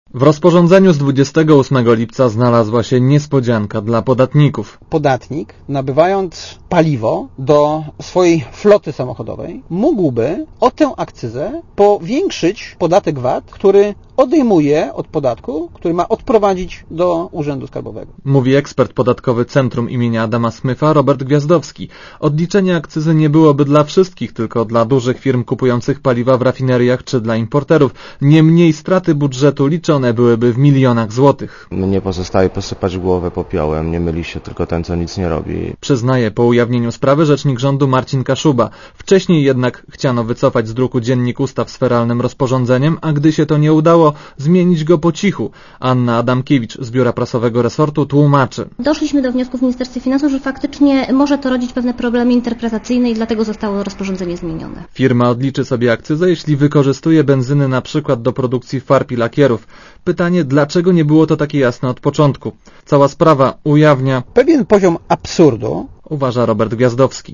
Relacja reportera Radia Zet (264Kb)